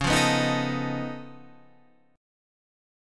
C# Augmented 9th